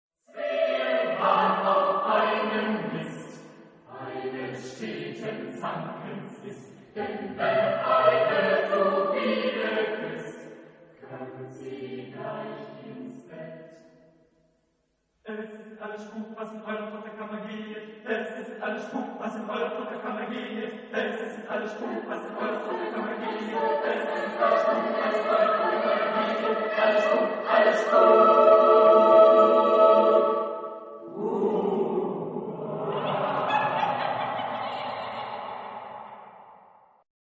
Genre-Style-Form: Cycle ; Choral song ; Secular
Type of Choir: SSSAATB  (7 mixed voices )
Tonality: free tonality